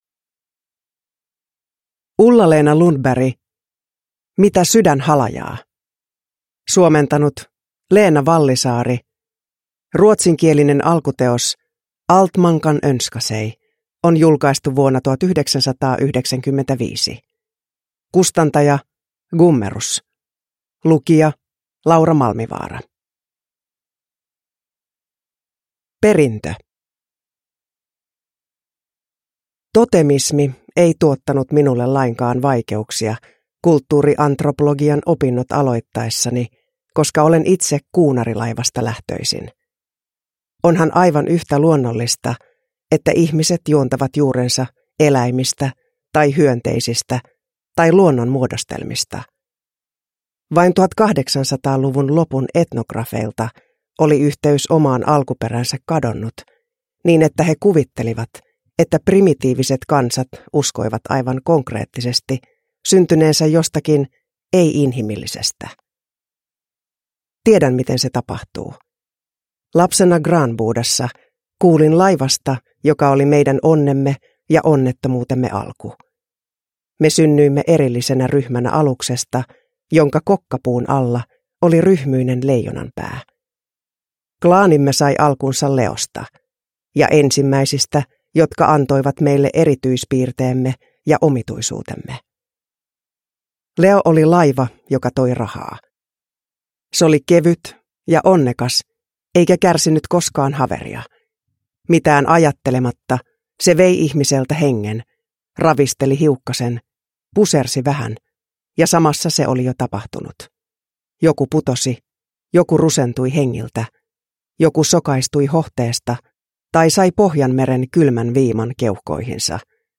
Mitä sydän halajaa – Ljudbok – Laddas ner
Uppläsare: Laura Malmivaara